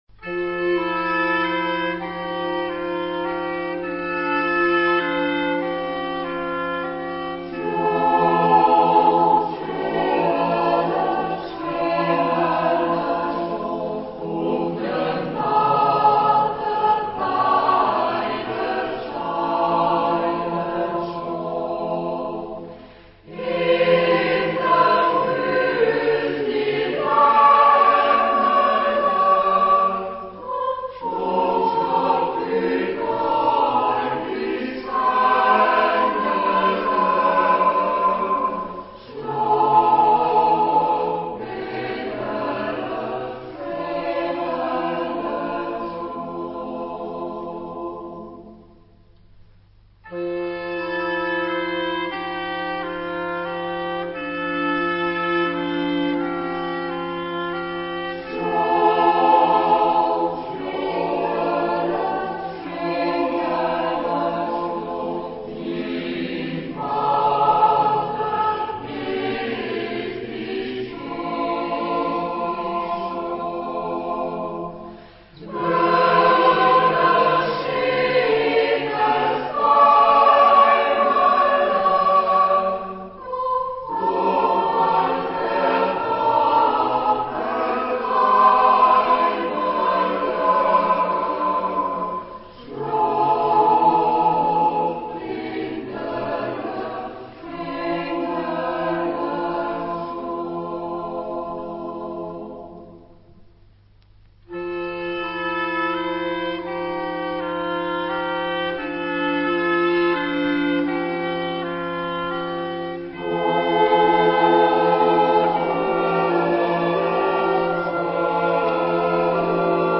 Género/Estilo/Forma: Popular ; Profano
Tipo de formación coral: SATB  (4 voces Coro mixto )
Tonalidad : fa mayor